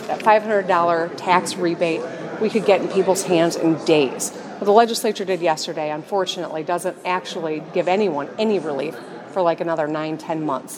Gov. Gretchen Whitmer was in Portage for the Grand Opening of the new Senior Center on Friday, May 20, and commented on the latest tax cut proposal from the Legislature.